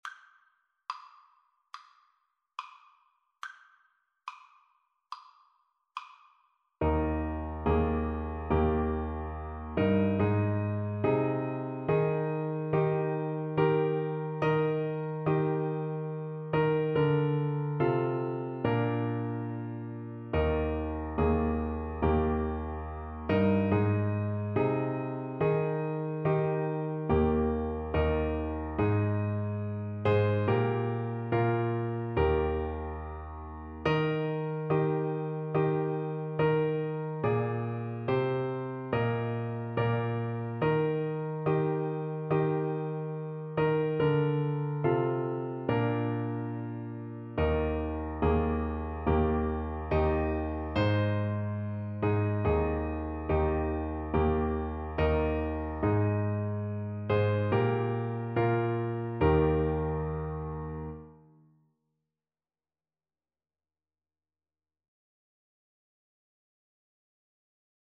Free Sheet music for Alto Saxophone
Alto Saxophone
Bb4-Bb5
Pieces in 4-4 Time Signature
Saxophone pieces in Eb major